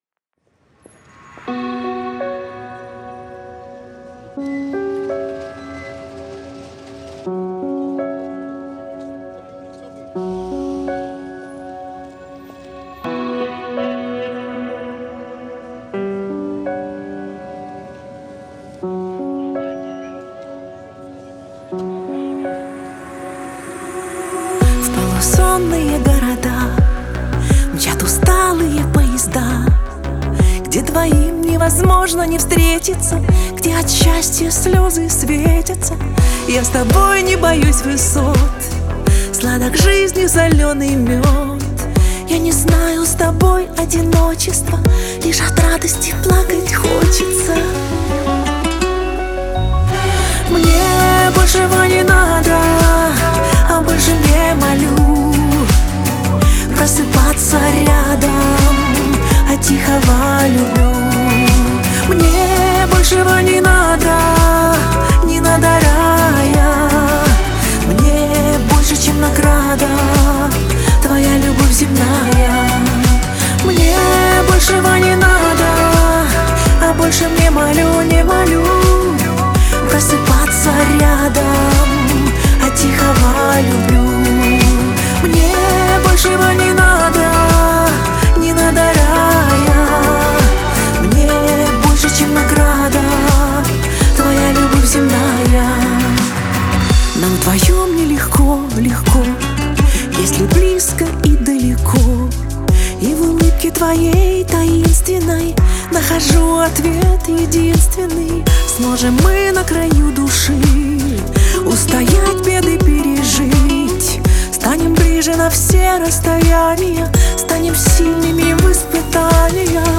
это трогательная и мелодичная песня в жанре поп